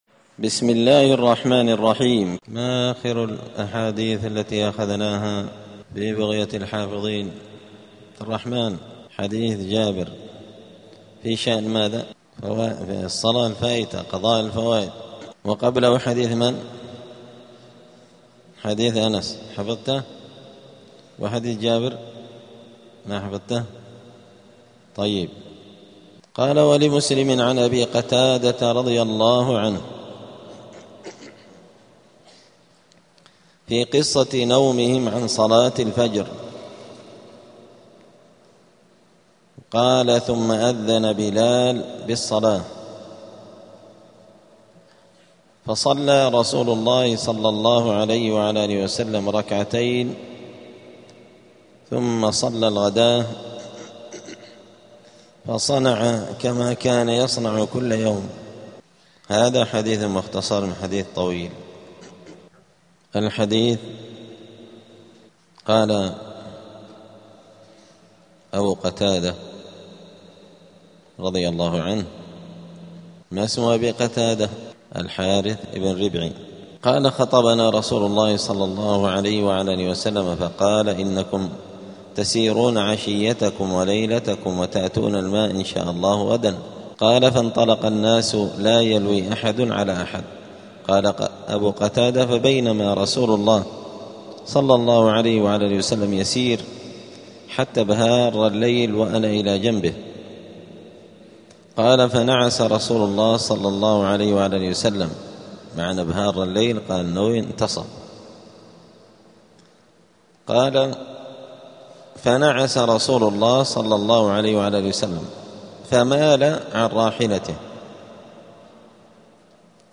دار الحديث السلفية بمسجد الفرقان قشن المهرة اليمن
*الدرس الثالث والأربعون بعد المائة [143] {هل قضاء الفائتة على الفور}*